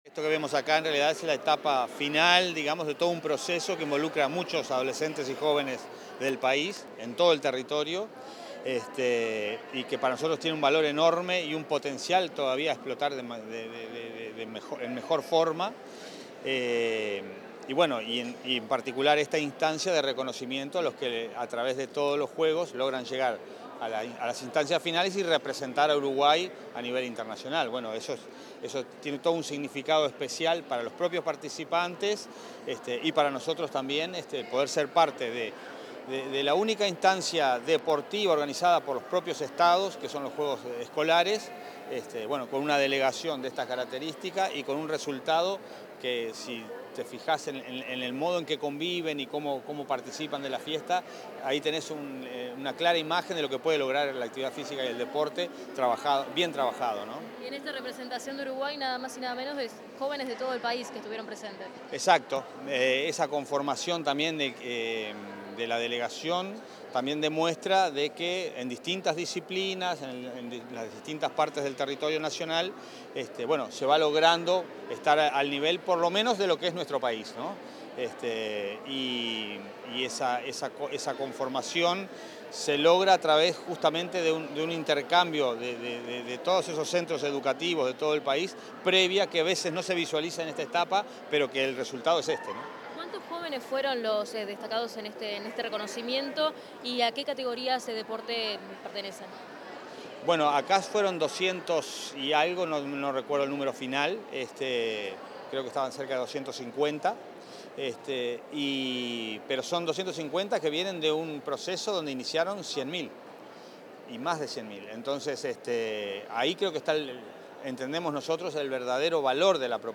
Declaraciones del secretario nacional del Deporte, Alejandro Pereda